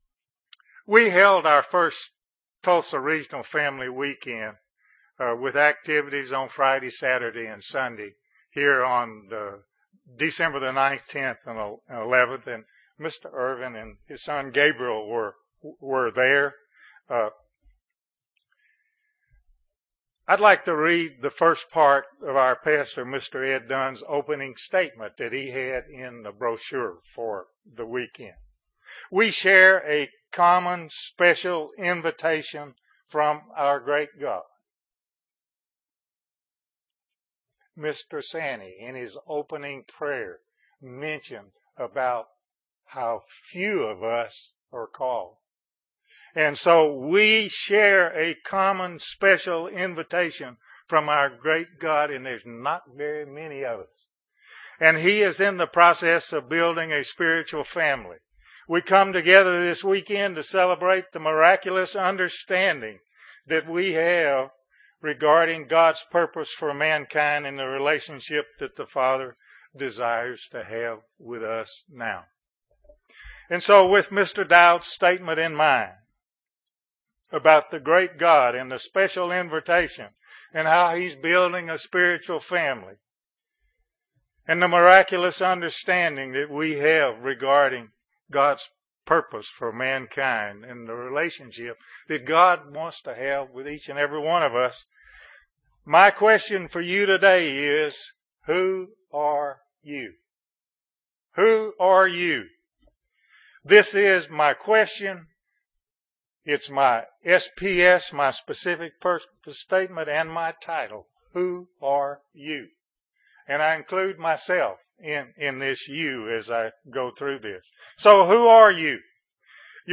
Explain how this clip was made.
Given in Northwest Arkansas